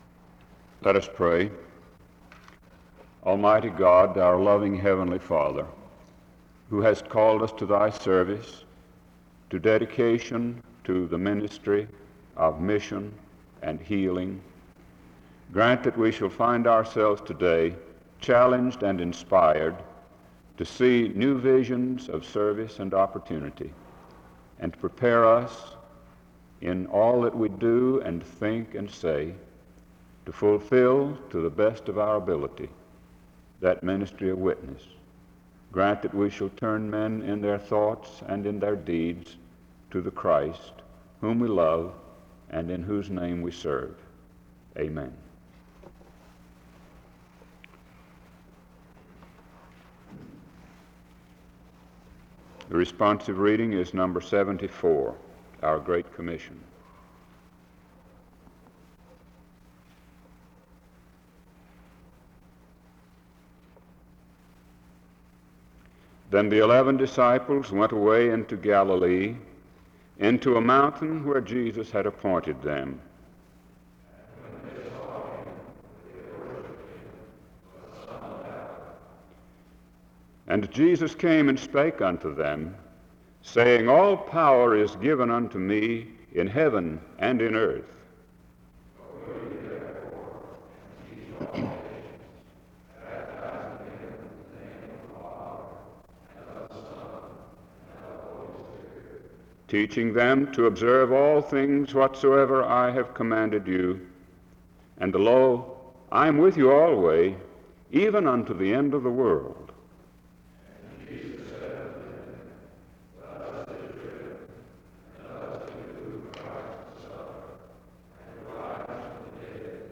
The service begins with a prayer (0:00-0:50). After which, the speaker reads a responsive reading (0:51-2:50).
The service closes in prayer (44:28-45:10).